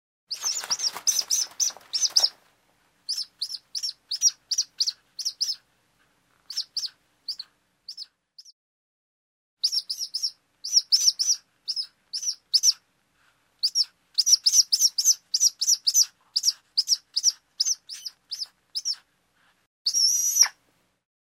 Звуки ястреба
Ястреб - для яркого разнообразия